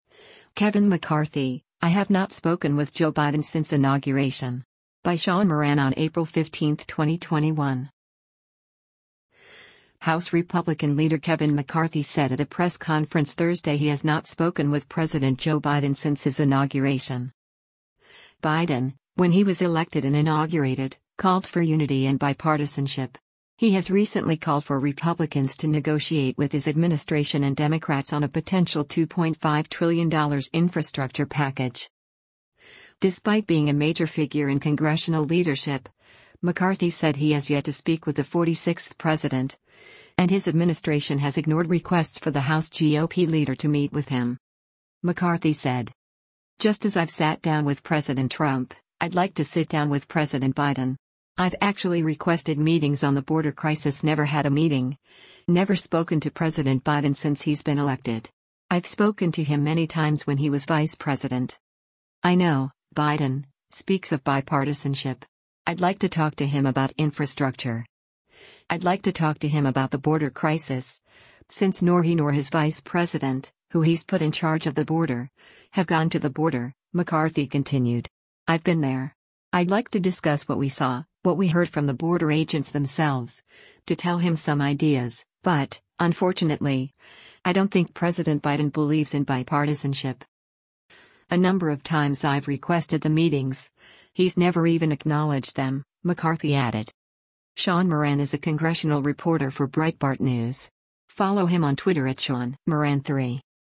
House Republican Leader Kevin McCarthy said at a press conference Thursday he has not spoken with President Joe Biden since his inauguration.